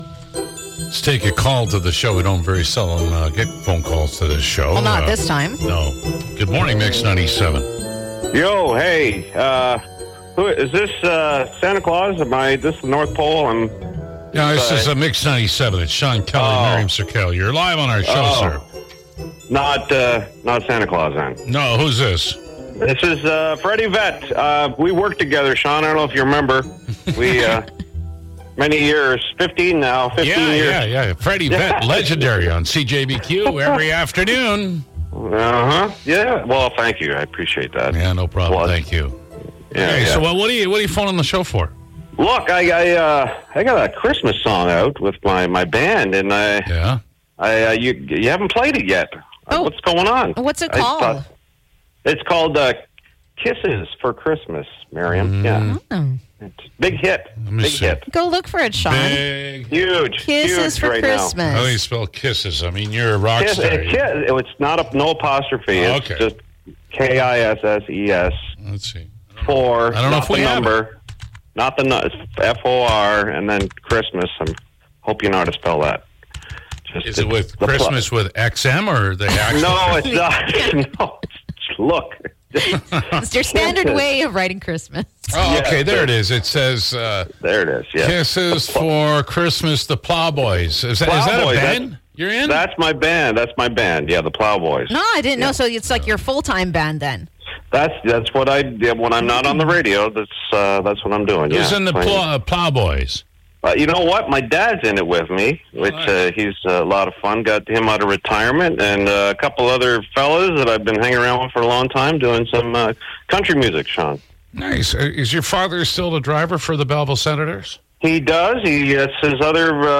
holiday song